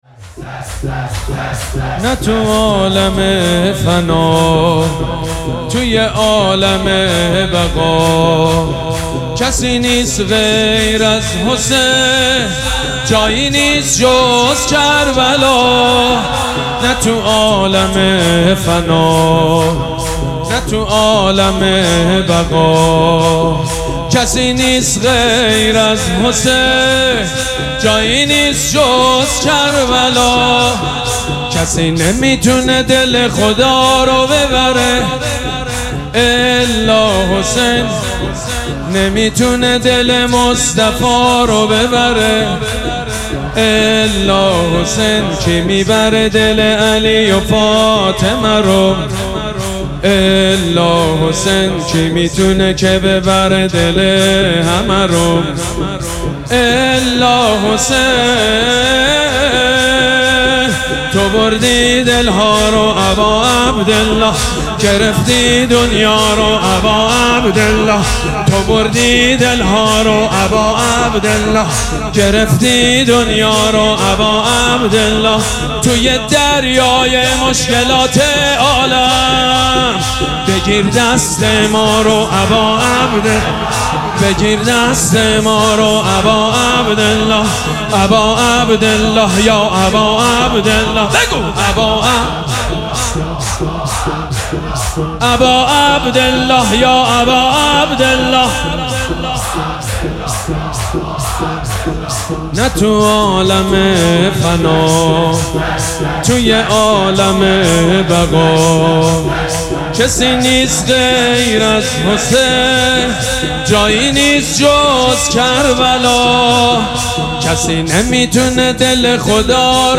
مداحی پر شور